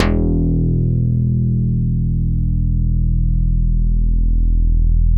Index of /90_sSampleCDs/Roland LCDP02 Guitar and Bass/BS _Synth Bass 1/BS _MIDI Bass